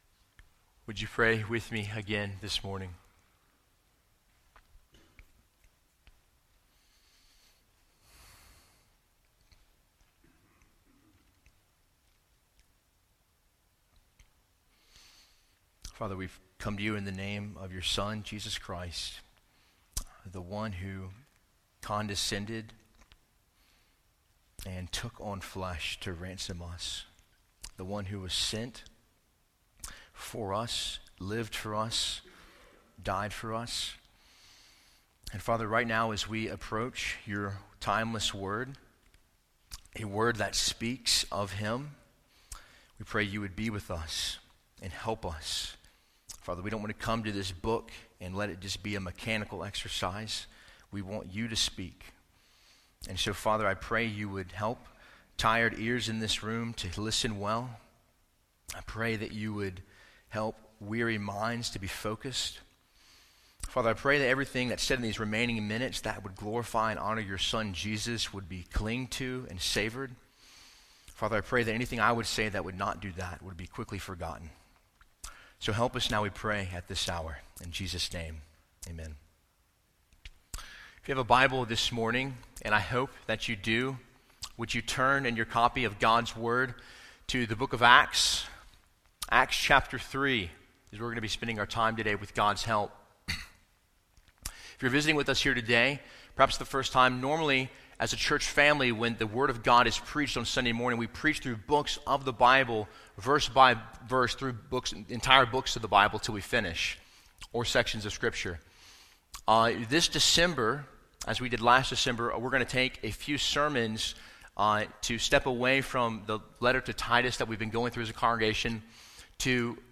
Sermon Audio 2019 December 1